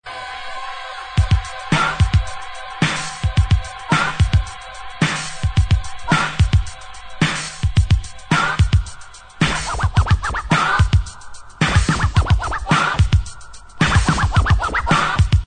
• Category Techno